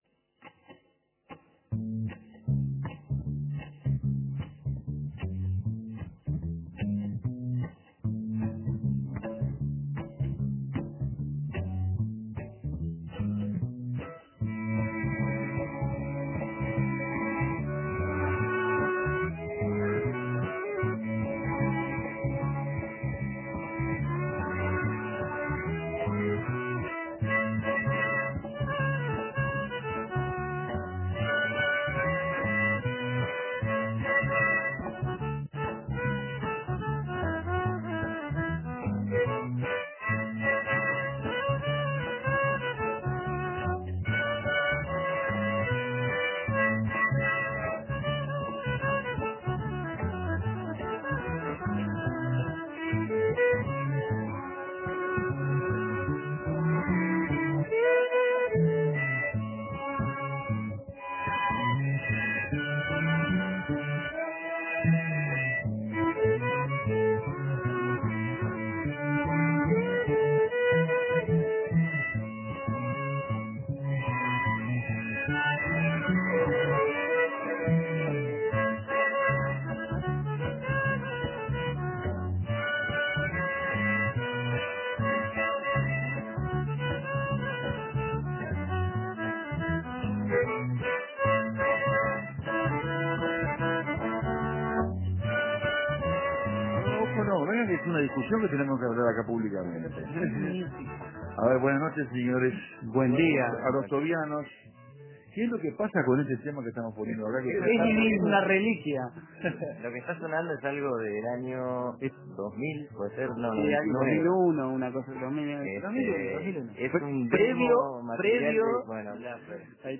Entre viola, violines y cello nos visitó el Club de Tobi